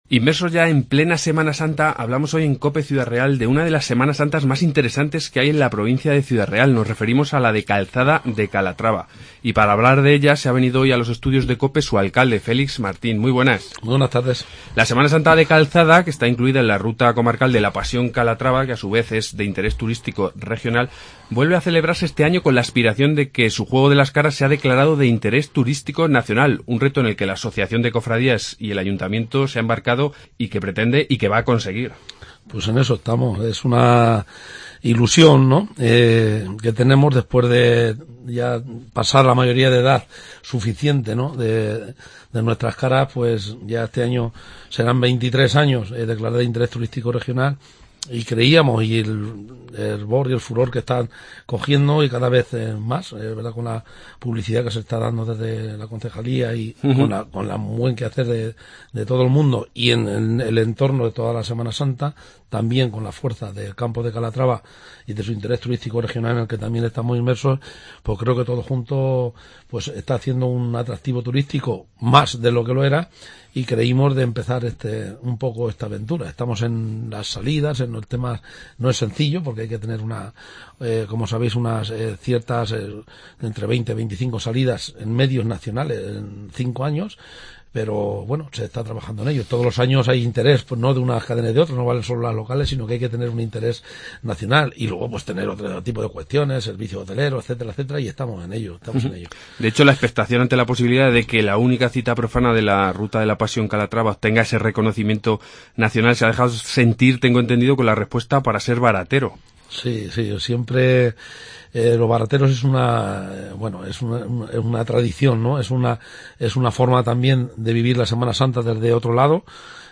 Entrevista Félix Martín (alcalde de Calzada) 21-3-16